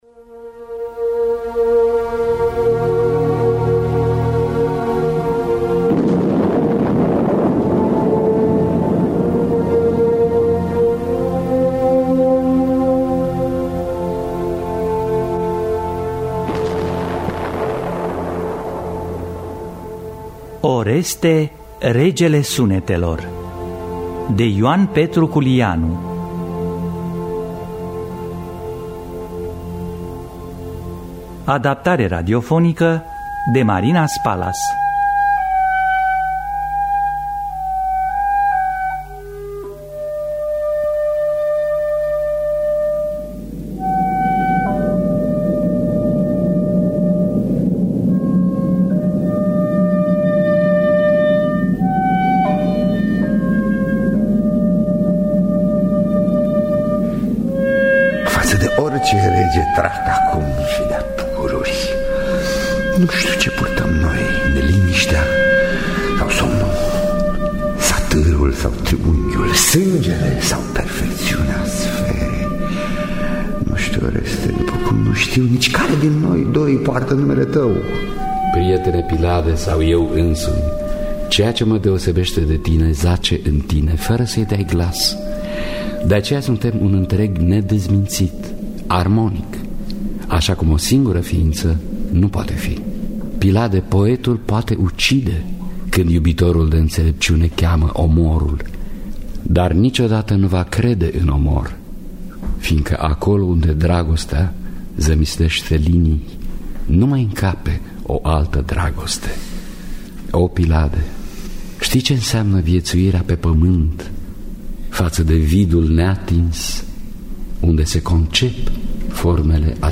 Adaptarea radiofonicã